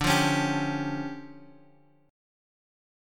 D Minor Major 7th Double Flat 5th